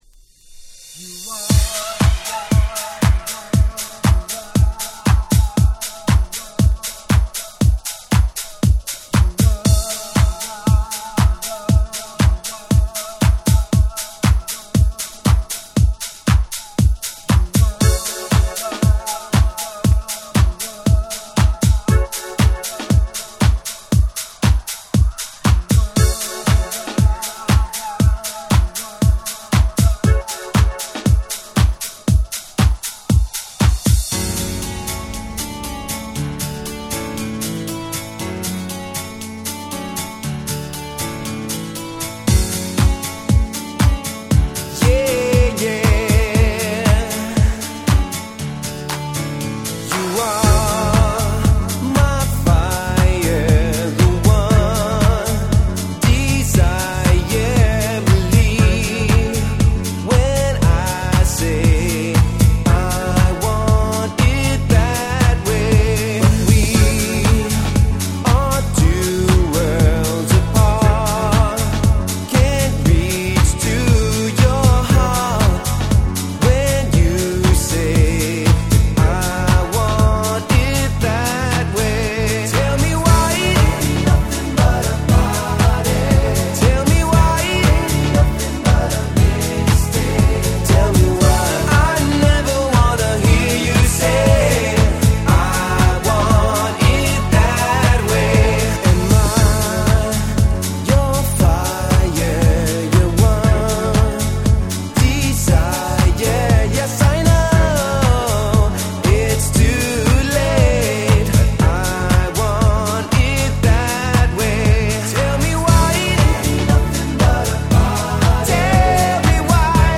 99' Nice Cover Dance Pop !!
これぞダンスポップの極み！！
キャッチー系